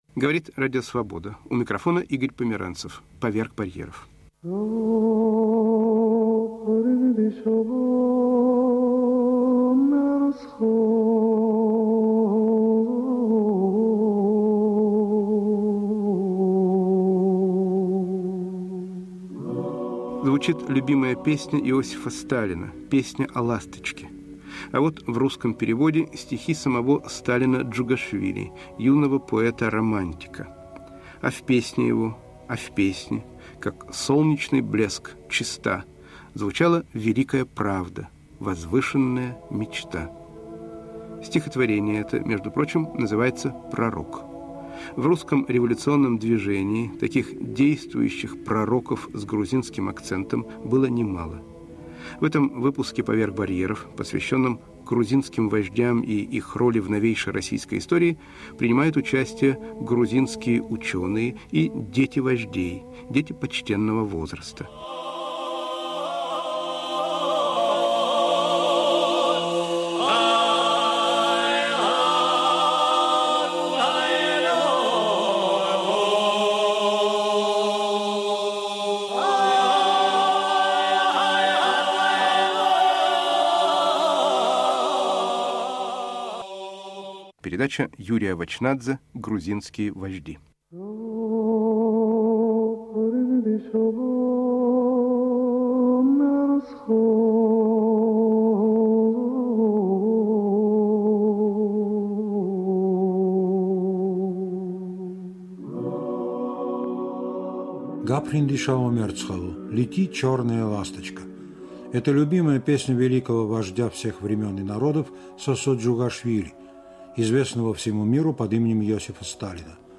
О роли грузинских революционеров в истории России ХХ века говорят грузинские учёные и потомки вождей